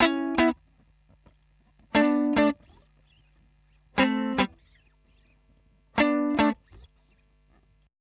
120_Guitar_funky_chords_E_1.wav